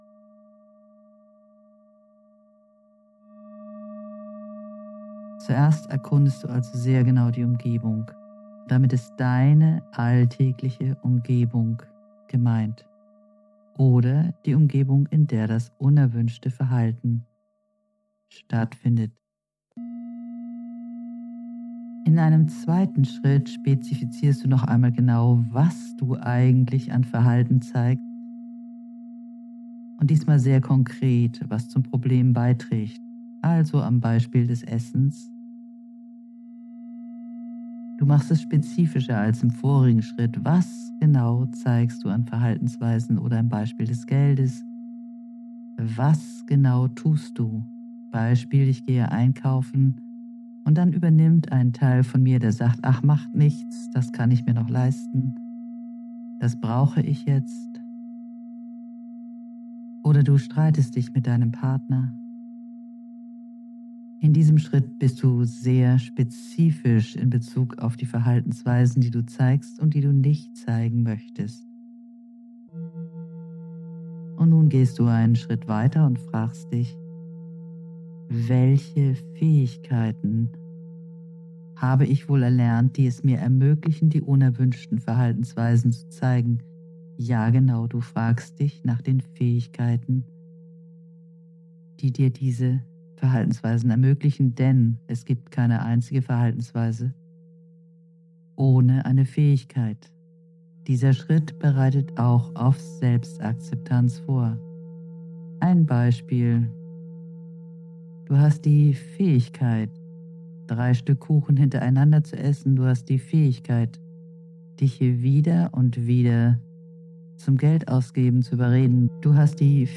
Die Sündenbockmeditation
Als Bonus gibt es eine kostenlose, geführte Meditation zum Download, die speziell darauf ausgerichtet ist, die Sündenbock-Identität aufzulösen.